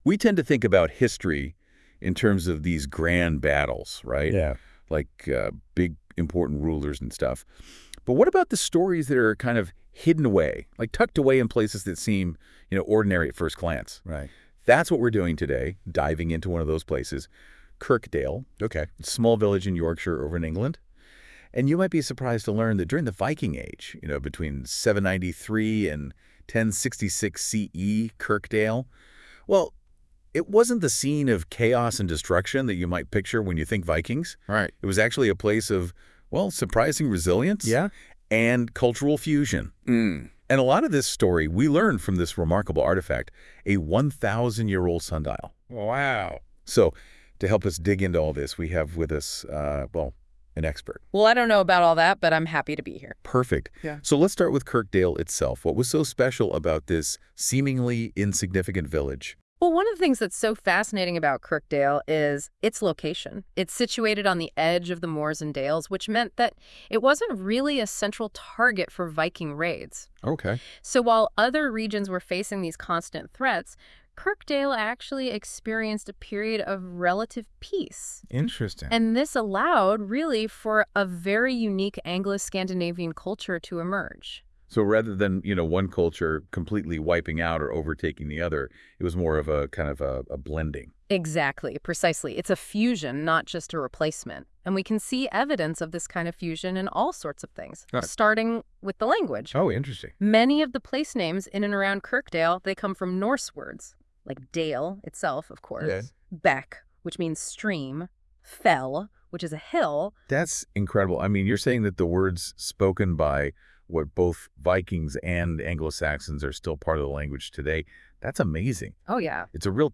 Using Google�s Notebook LM, listen to an AI powered podcast summarising this page.